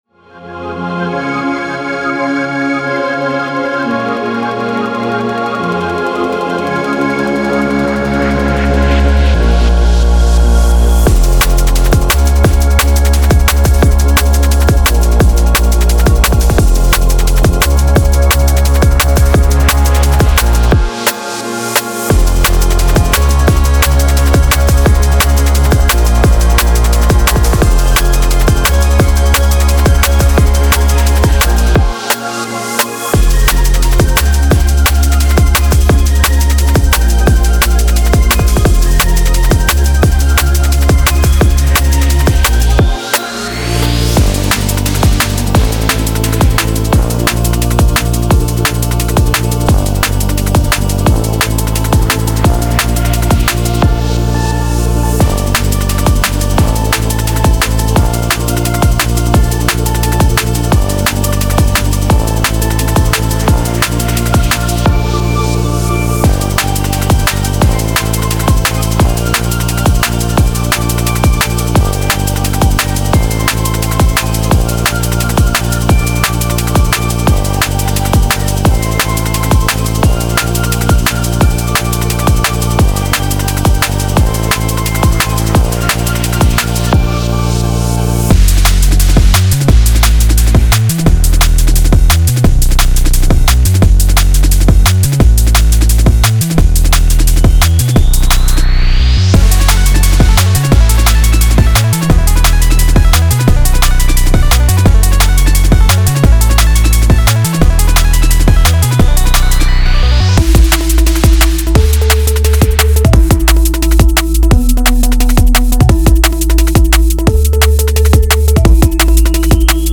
Genre:Drum and Bass
中には、174 BPMというリキッド特有のテンポを中心に構築されたサウンドが幅広く揃っています。
デモサウンドはコチラ↓